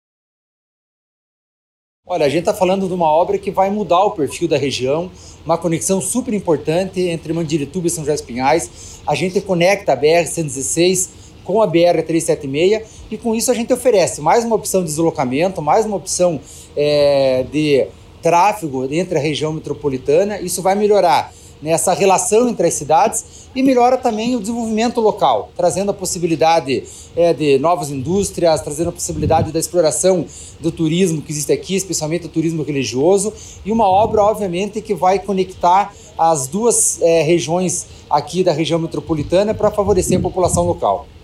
O diretor-presidente da Amep, Gilson Santos, fala sobre a importância da obra.